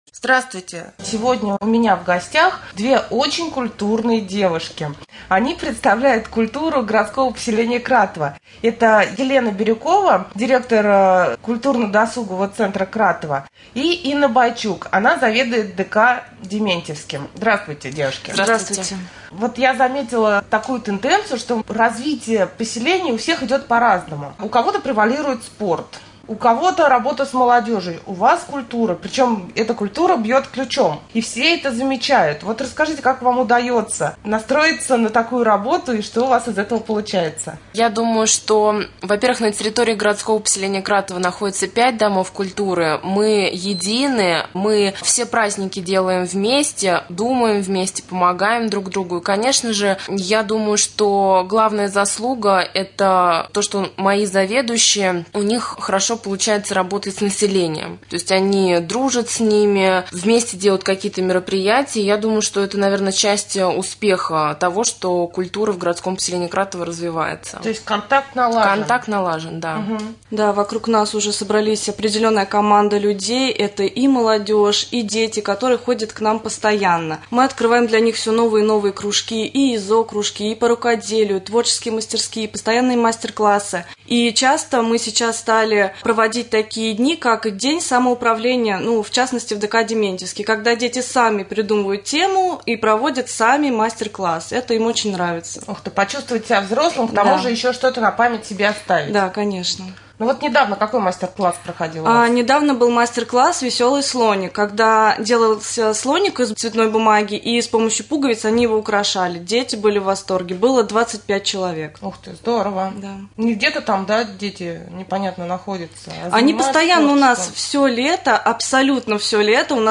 1. Прямой эфир с председателем Комитета по образованию Н.А.Асеевой